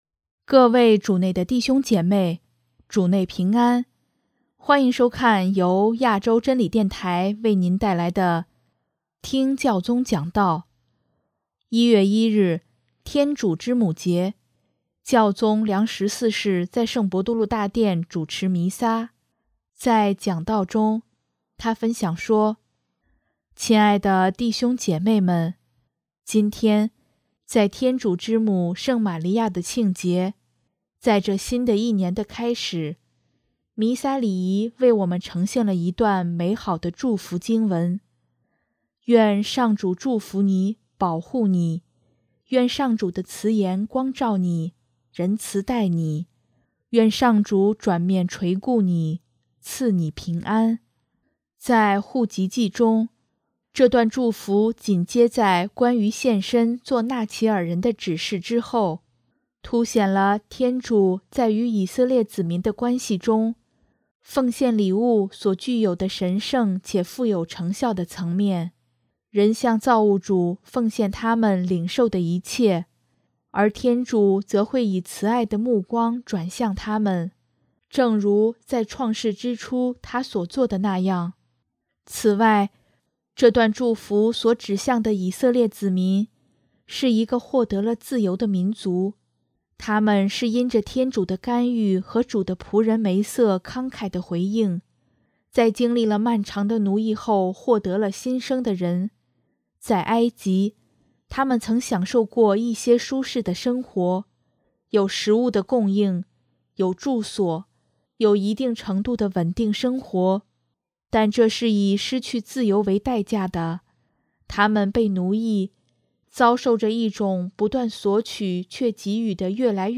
1月1日，天主之母节，教宗良十四世在圣伯多禄大殿主持弥撒，在讲道中，他分享说：